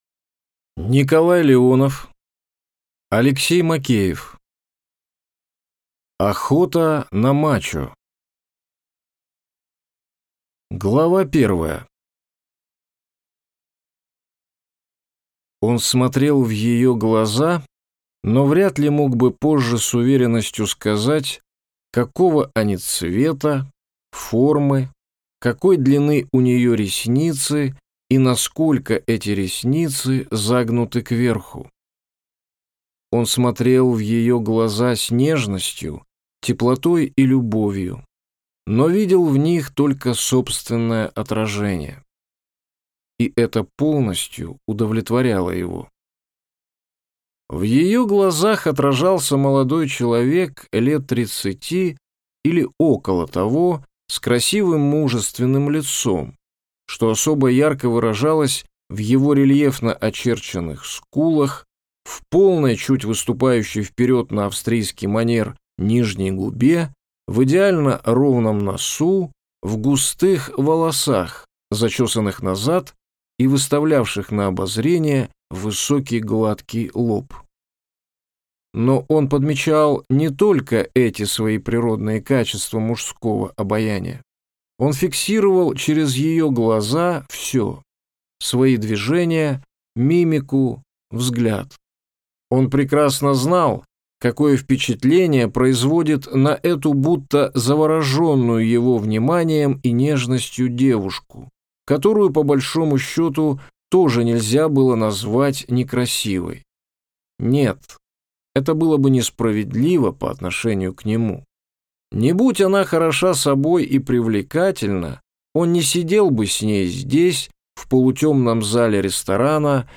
Аудиокнига Охота на мачо | Библиотека аудиокниг